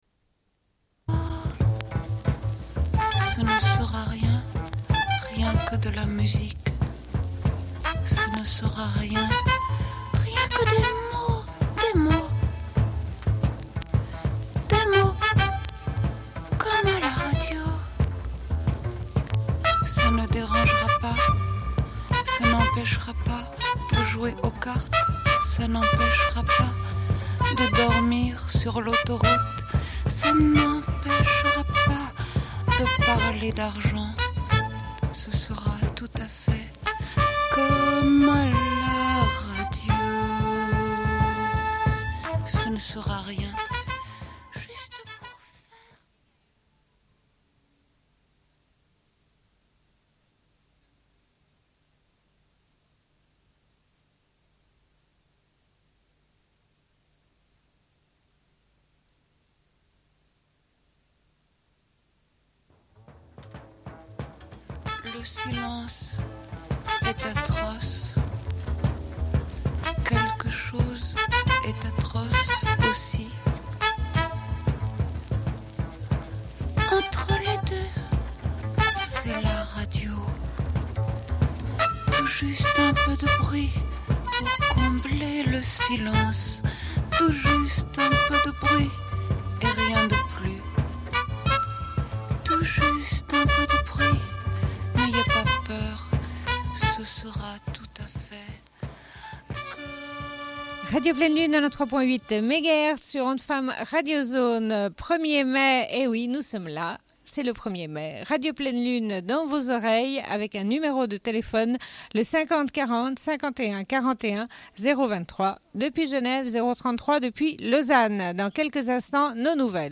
Une cassette audio, face B00:28:47
Bulletin d'information de Radio Pleine Lune. Retour sur le cortège du 1er mai à Genève, où les femmes étaient à la tête pour appeler à la grève pour le 14 juin 1991.